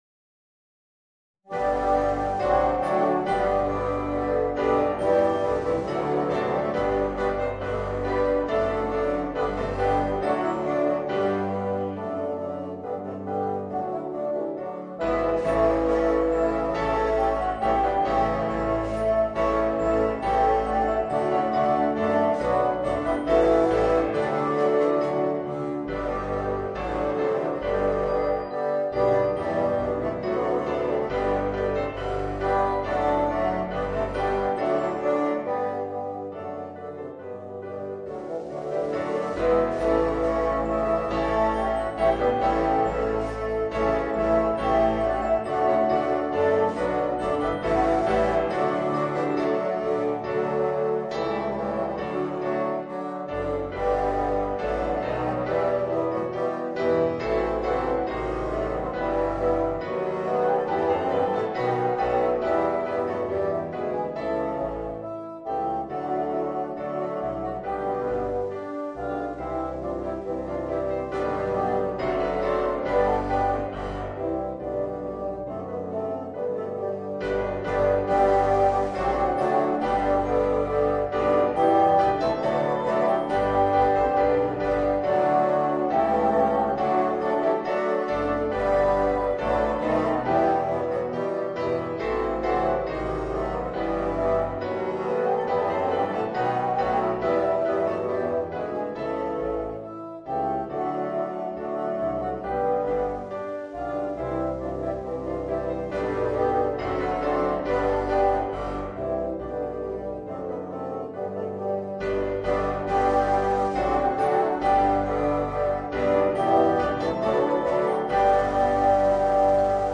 Voicing: 8 Bassoons